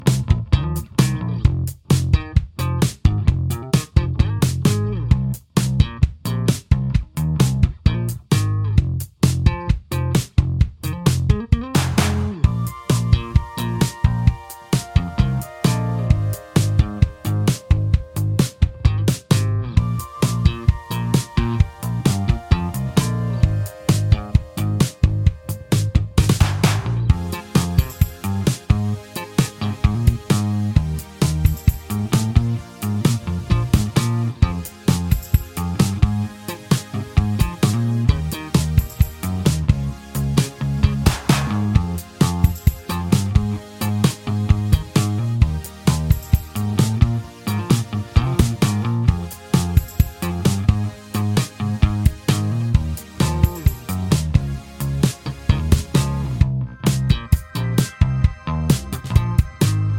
Minus Main Guitars For Guitarists 4:25 Buy £1.50